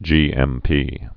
(jēĕm-pē)